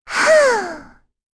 Rehartna-Vox_Sigh.wav